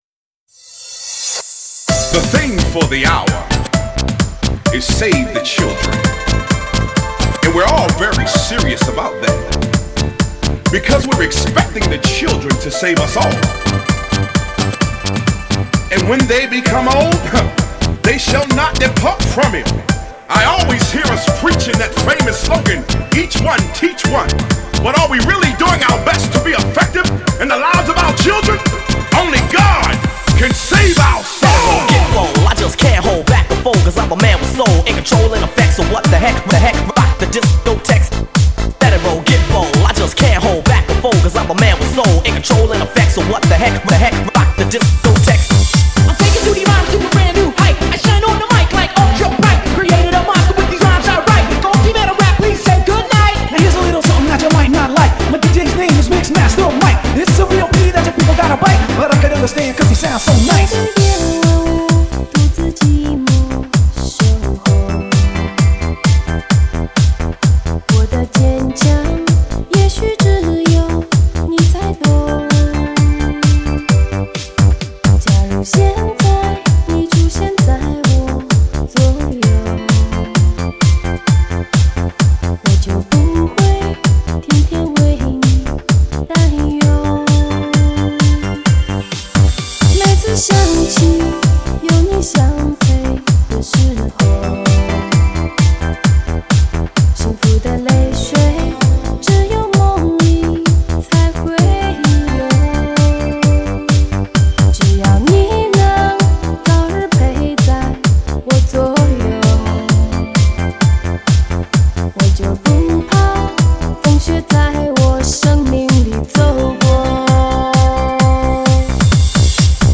2周前 车载音乐 8